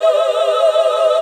H015VOCAL.wav